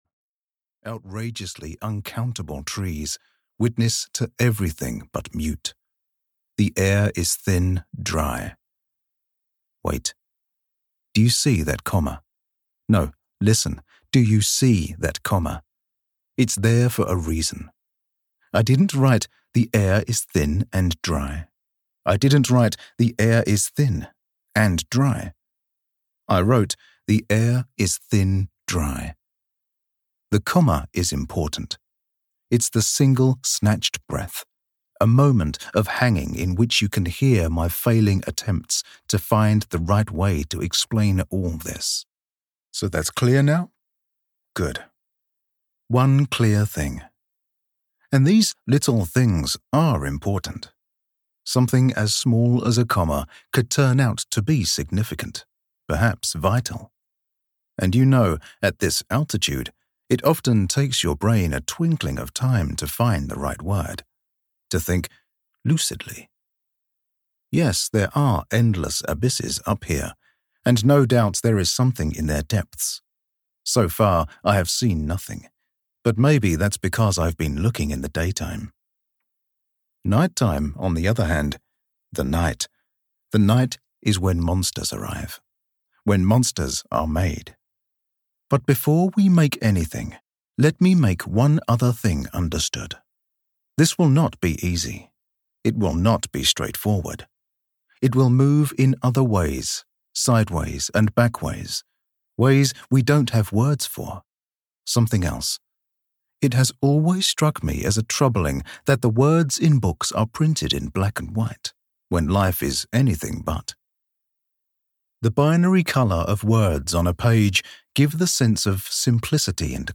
Audiobook The Monsters We Deserve written by Marcus Sedgwick.
Ukázka z knihy